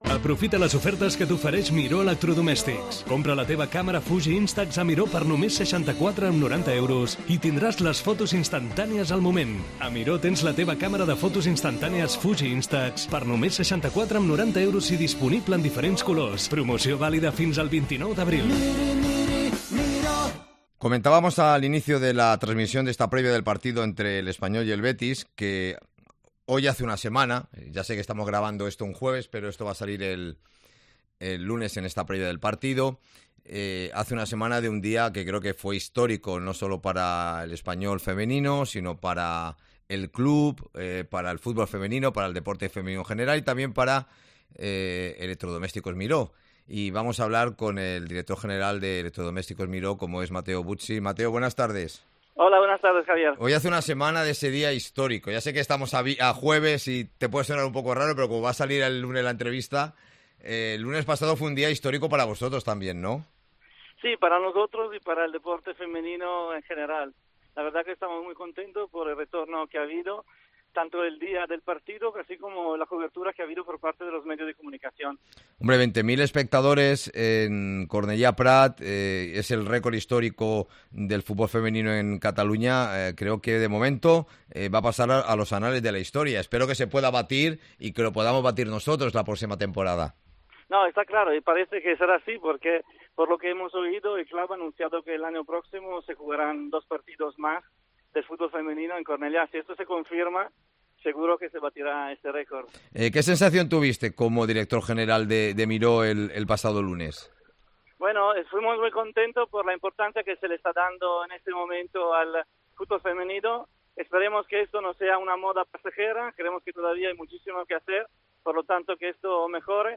Tiempo de Espanyol Entrevista